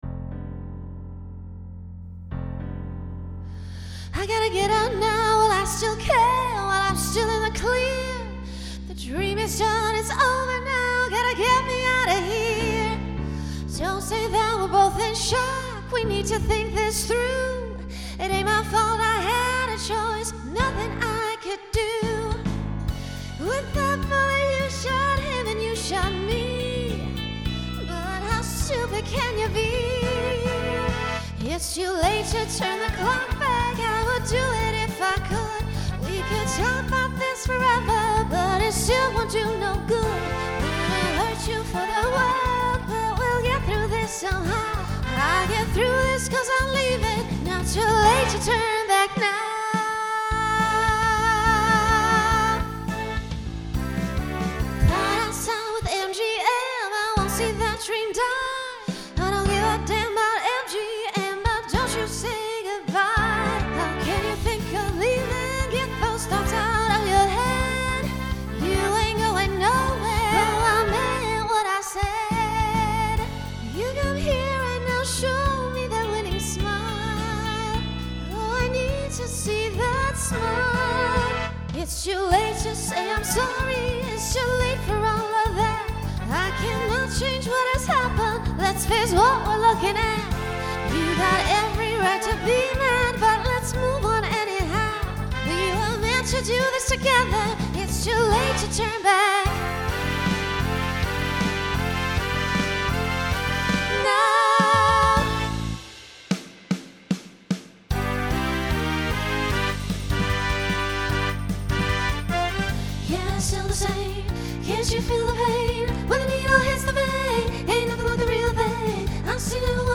Broadway/Film , Pop/Dance
Voicing SSA